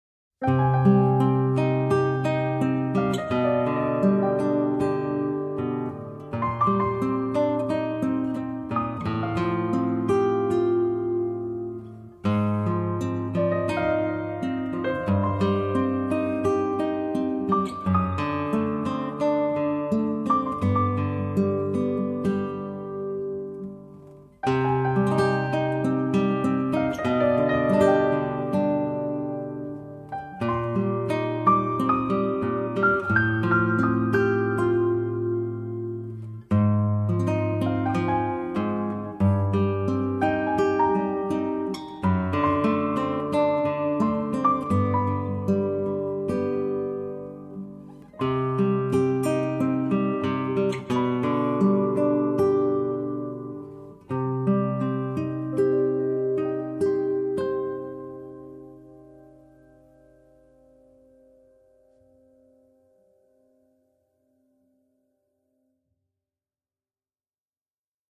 Guitar And Piano